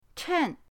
chen4.mp3